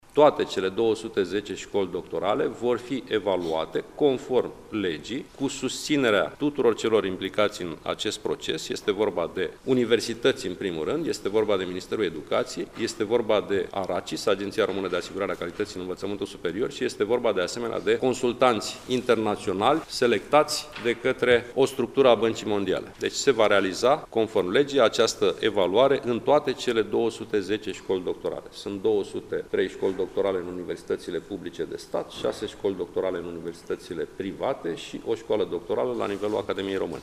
După întâlnirea Consiliului Naţional al Rectorilor, eveniment care a avut loc la Iaşi, preşedintele Consiliului Sorin Câmpeanu, a precizat că din comisia de evaluare vor face parte membri ai corpului academic şi universitar din România de la Ministerul Educaţiei, de la Agenţia Română de Asigurare a Calităţii în Învăţământul Superior (ARACIS) precum şi o echipă de consultanţi internaţionali: